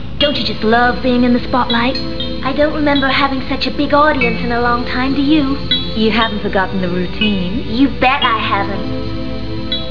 Here are some .wav files from Part 1 Acts I&II so I'll let the characters speak for themselves.